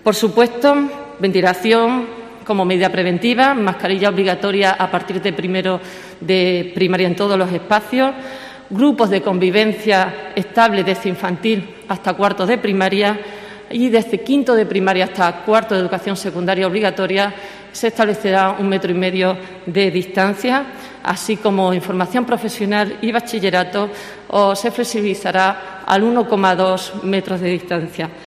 Esther Gutiérrez, consejera de Educación, sobre el curso escolar 2021-2022.